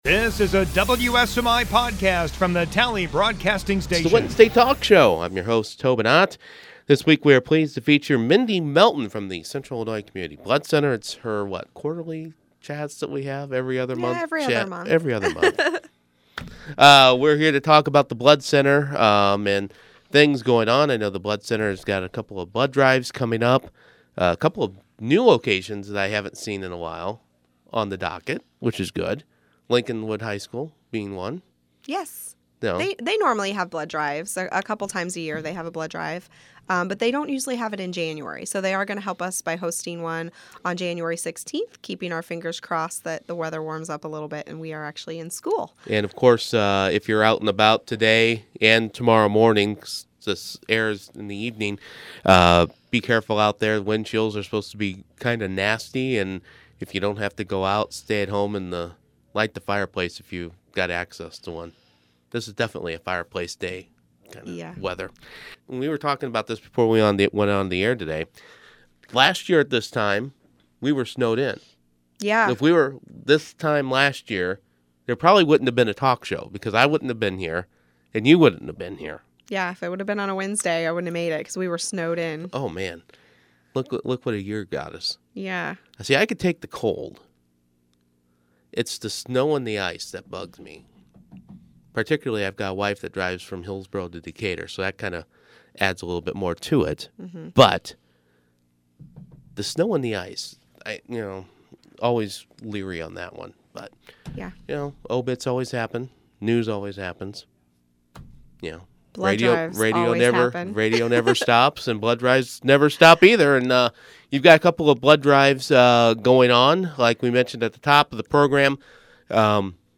Wednesday Morning Talk Show